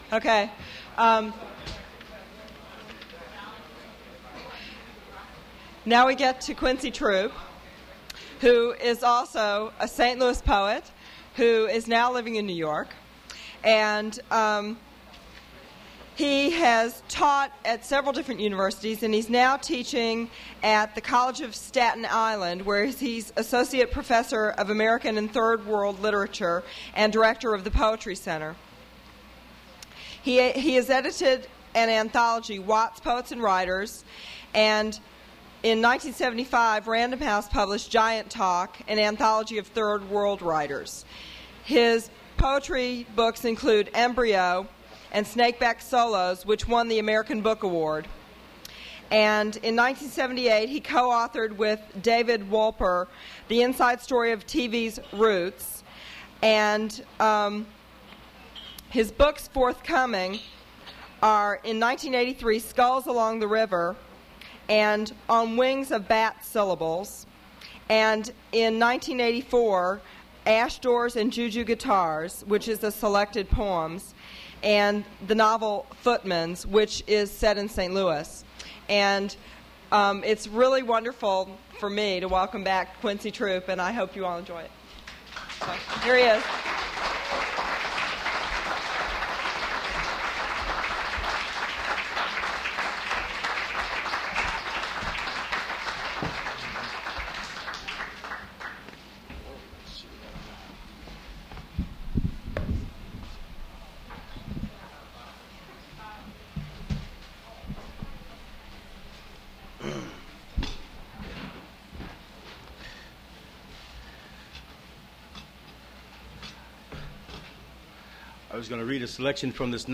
Poetry reading featuring Quincy Troupe
Attributes Attribute Name Values Description Quincy Troupe reading his poetry at Duff's Restaurant.
mp3 edited access file was created from unedited access file which was sourced from preservation WAV file that was generated from original audio cassette.